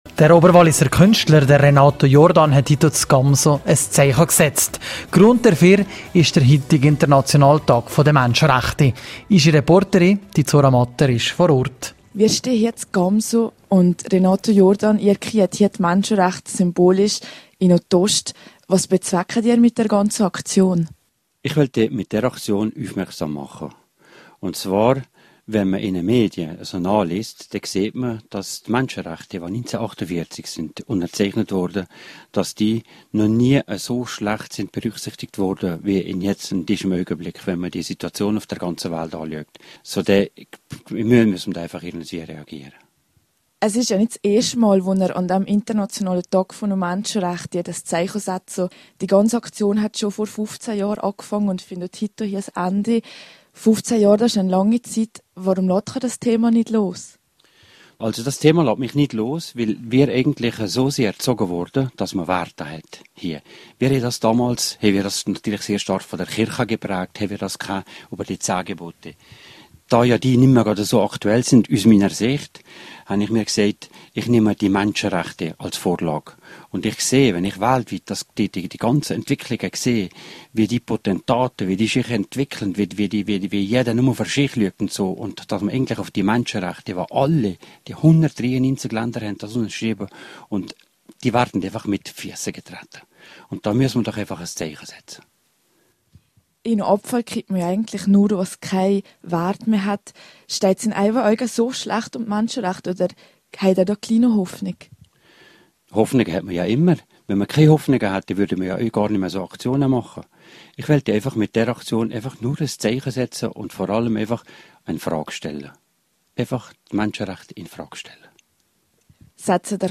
Tag der Menschenrechte: Interview